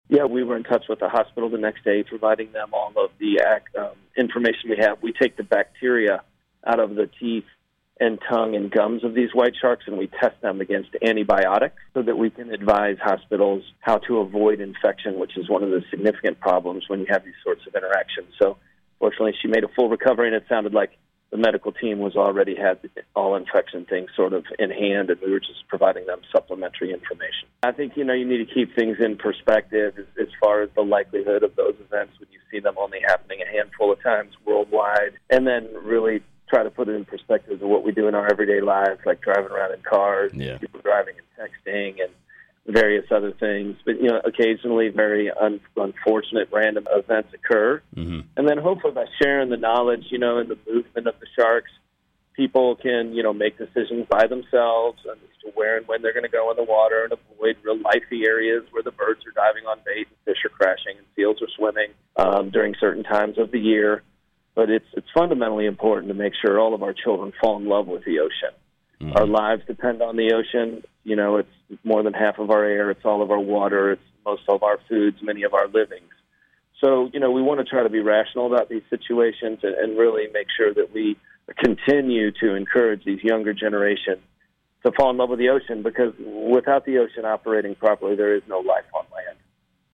We spoke with OCEARCH Founder and Expedition Leader Chris Fischer about this attack and he let us know that they offered assistance immediately.